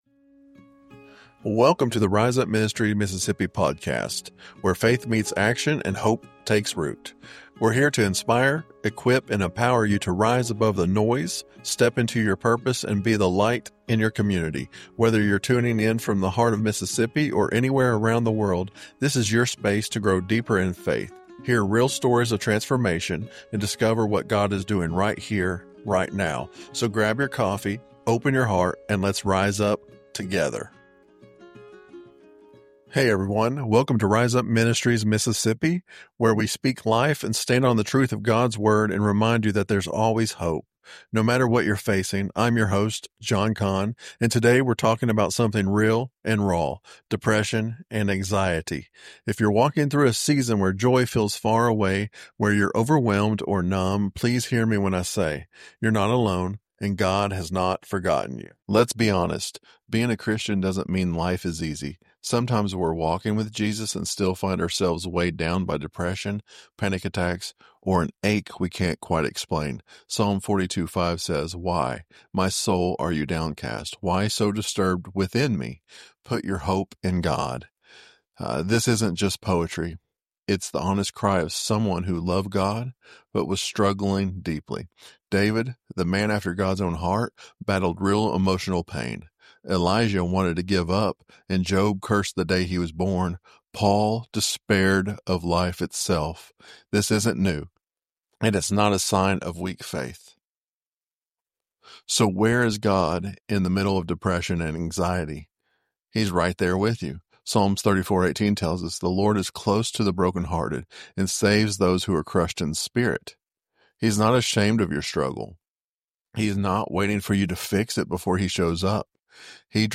He shares biblical insights and practical responses to mental health challenges, reminding listeners of God's unwavering presence and love during difficult times. The episode concludes with a heartfelt prayer for healing and hope.